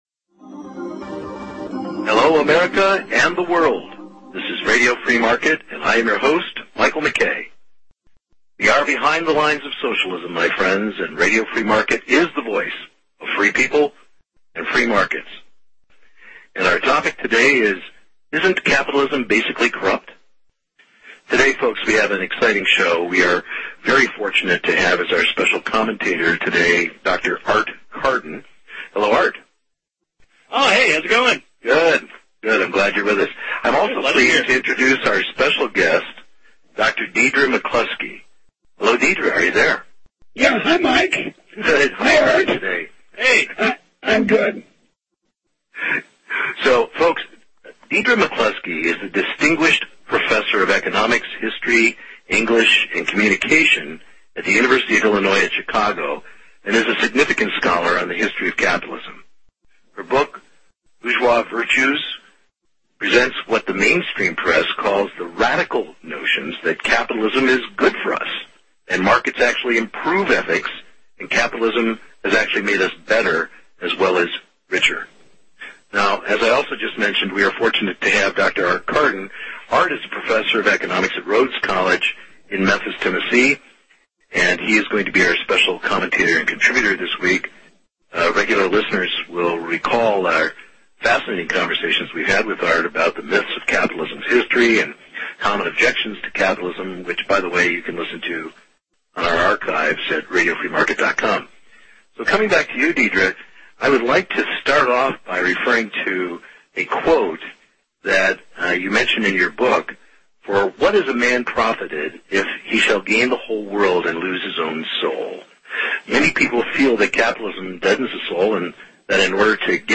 *Isn’t Capitalism Basically Corrupt?* A Very Special Interview with Dr. Deirdre McCloskey, Distinguished Professor of Economics, History, English and Communications at the University of Illi…
(Due to technical issues the sound quality of this interview may be challenging.